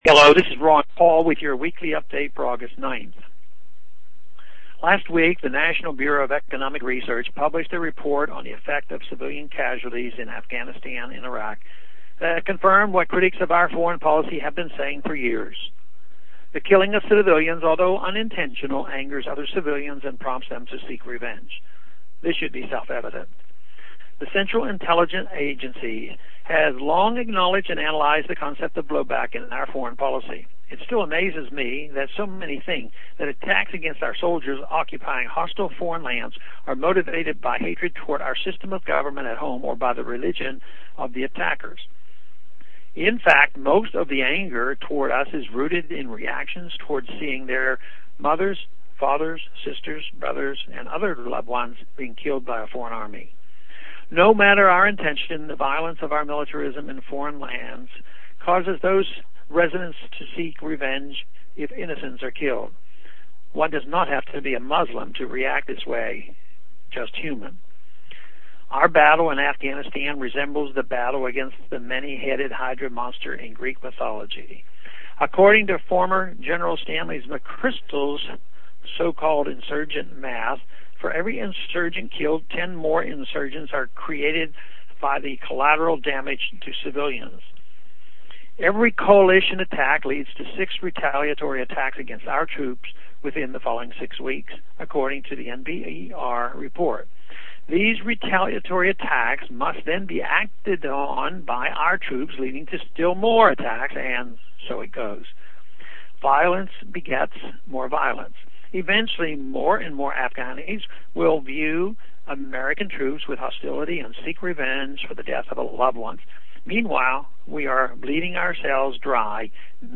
Listen to Ron Paul deliver these remarks